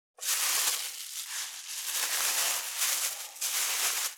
641コンビニ袋,ゴミ袋,スーパーの袋,袋,買い出しの音,ゴミ出しの音,袋を運ぶ音,
効果音